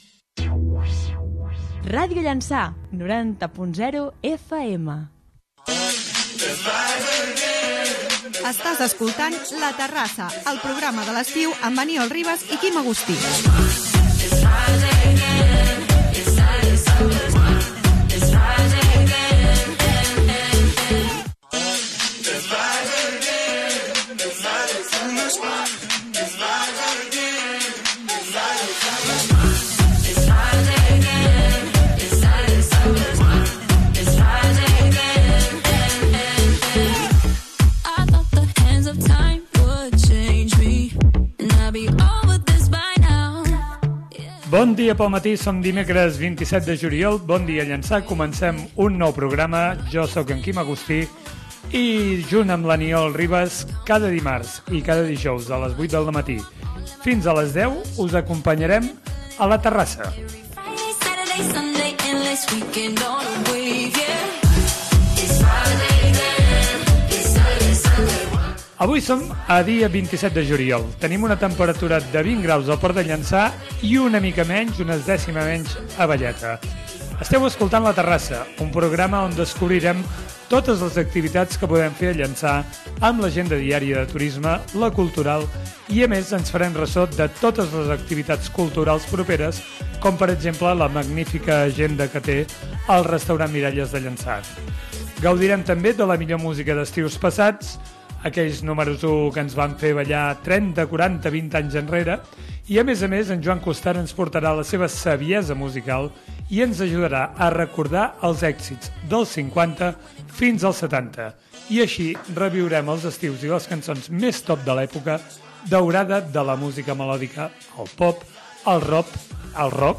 Indicatiu de l'emissora, careta del programa i inici del programa, amb el sumari de continguts.
Entreteniment